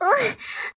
combobreak.ogg